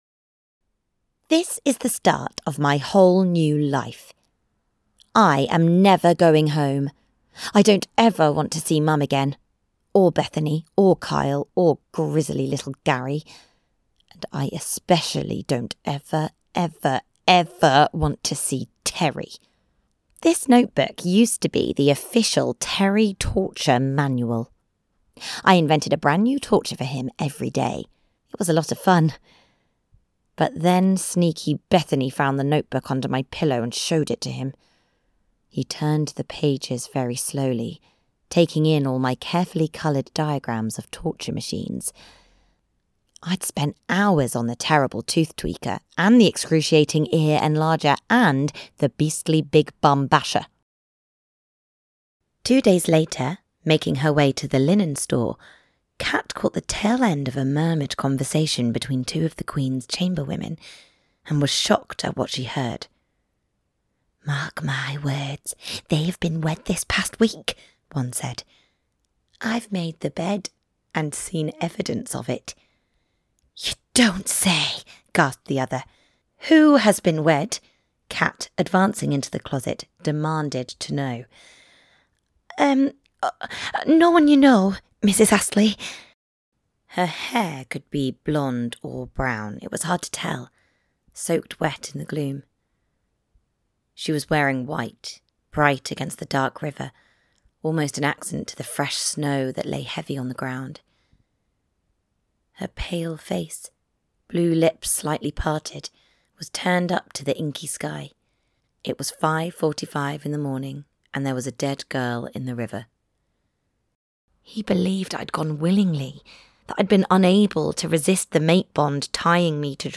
Playing age: Teens - 20s, 20 - 30sNative Accent: Estuary, Liverpool, RPOther Accents: American, Australian, Estuary, Liverpool, London, Neutral, Newcastle, Northern, Welsh, West Country
• Native Accent: Estuary, RP, Liverpool
• Home Studio